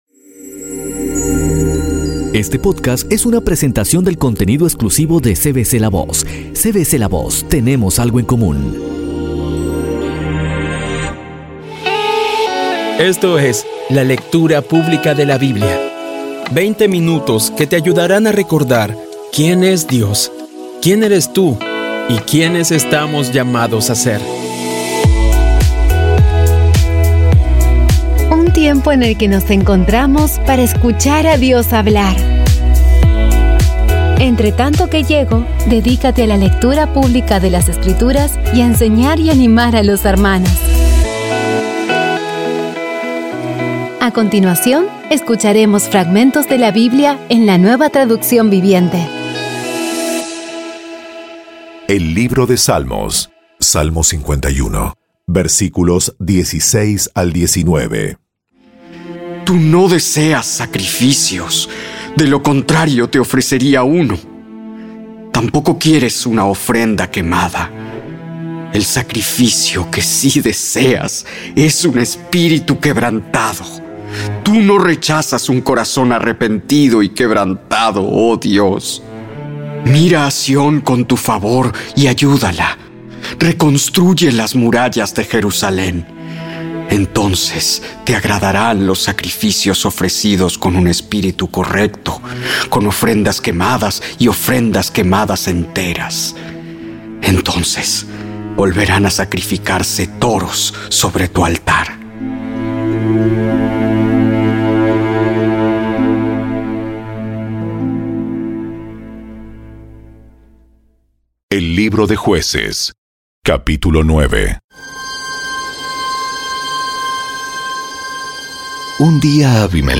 Audio Biblia Dramatizada Episodio 117
Poco a poco y con las maravillosas voces actuadas de los protagonistas vas degustando las palabras de esa guía que Dios nos dio.